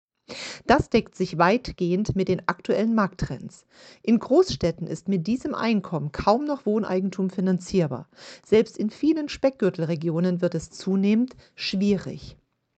Sie ist Immobilienexpertin und Maklerin.